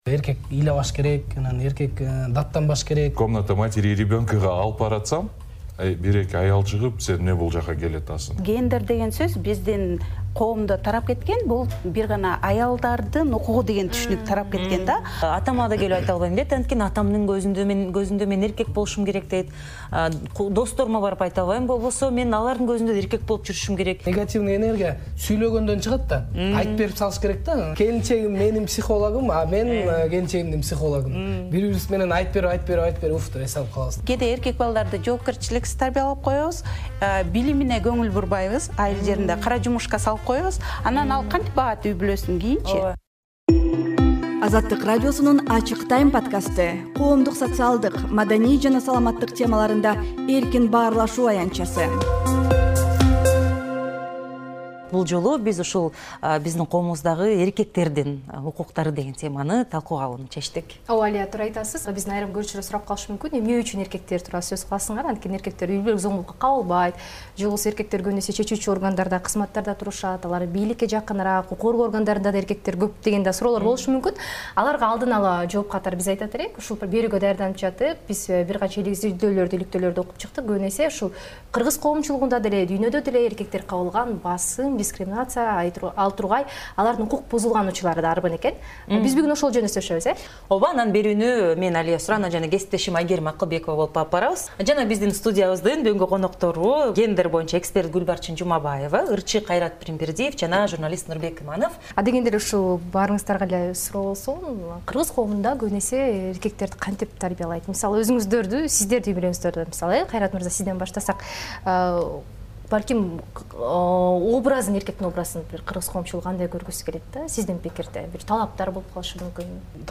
Кыргыз коомундагы эркектерге карата болгон басым, дискриминация жана стереотиптерди талкуулайбыз. "Эже-сиңдилер" программасындагы бул талкуунун аудиосун "Ачык Time" подкаст аянтчасында сунуш кылабыз.